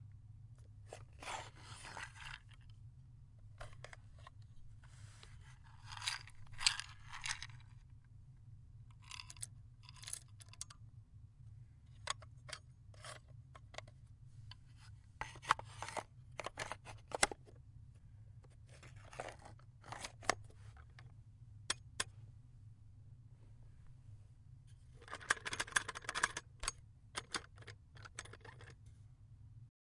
古董咖啡研磨机的其他声音
描述：来自空的老式/古董手摇曲柄咖啡研磨机（大约20世纪30年代）的各种声音，带有空心木制底座和抽屉以捕捉研磨。打开/关闭抽屉，拨浪鼓手柄，龙头底座，打开/关闭料斗。